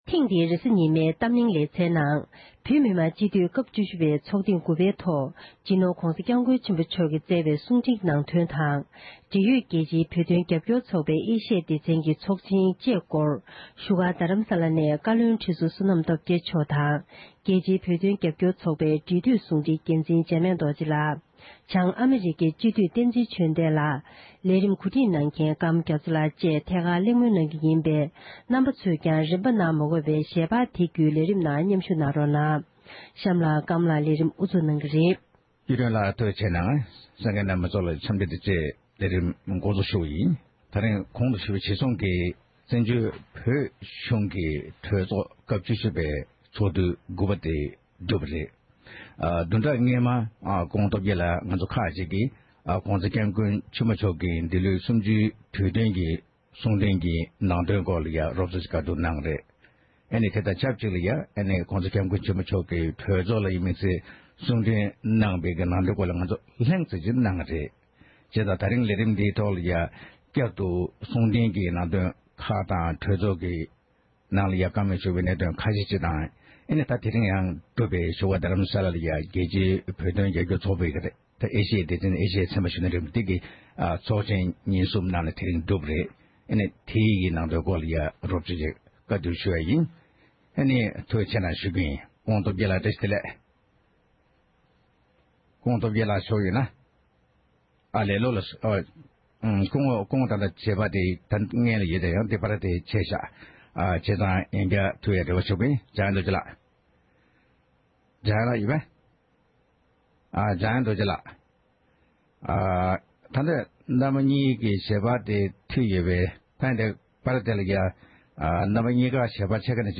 ཐེངས་འདིའི་རེས་གཟའ་ཉི་མའི་གཏམ་གླེང་གི་ལེ་ཚན་ནང་།